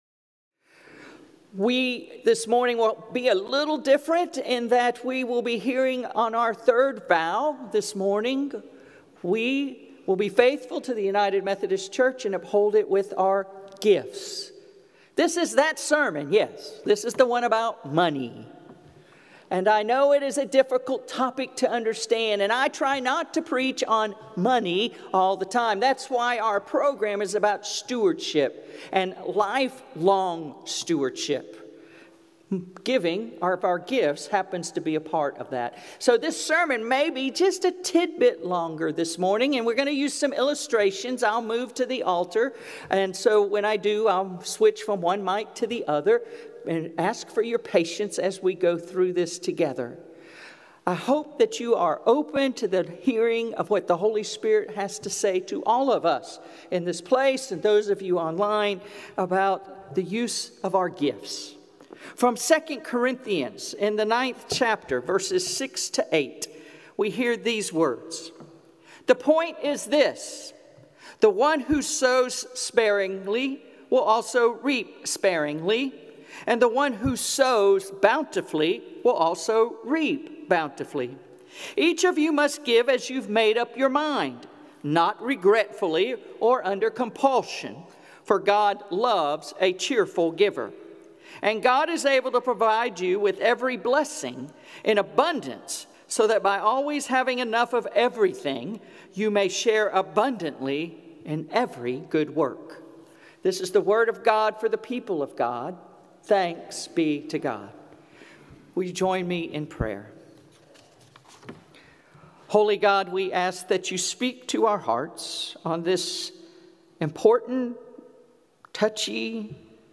Sermons | Kingswood Church in Dunwoody, Georgia